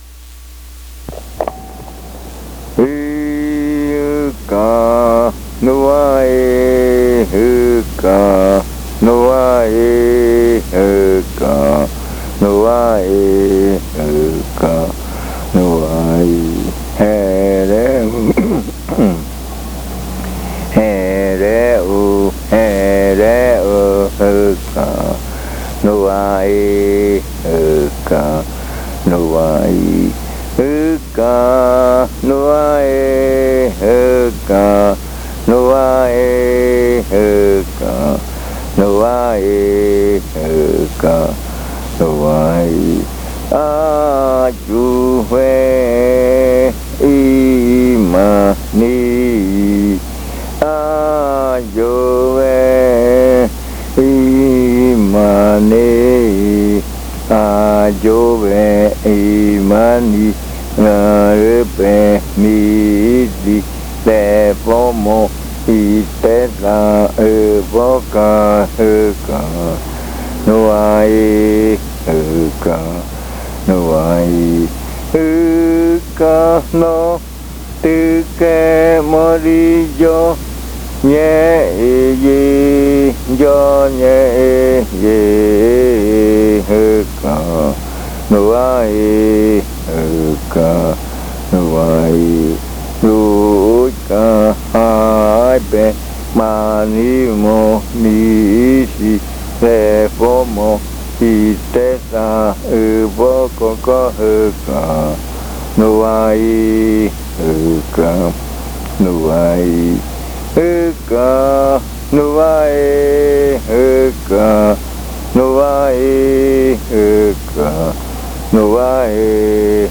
Leticia, Amazonas
This chant is part of the collection of chants from the Yuakɨ Murui-Muina (fruit ritual) of the Murui people